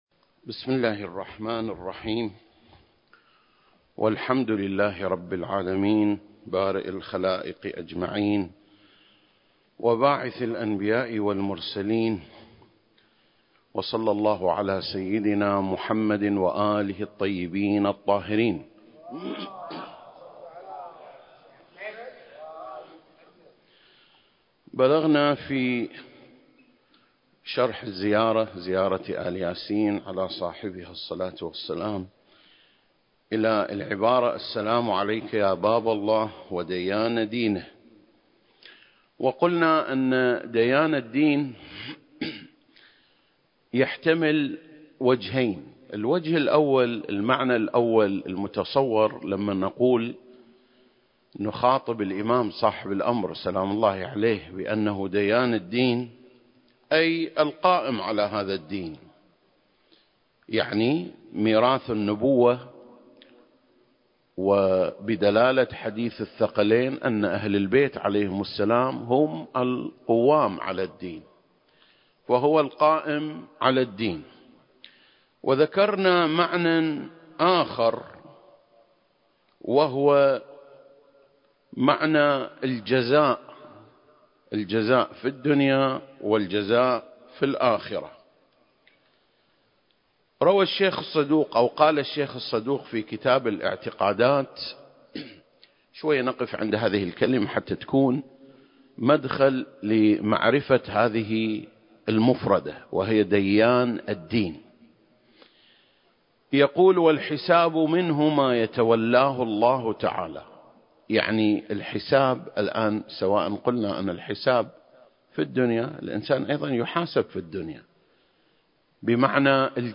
سلسلة: شرح زيارة آل ياسين (38) - ديان دينه (2) المكان: مسجد مقامس - الكويت التاريخ: 2021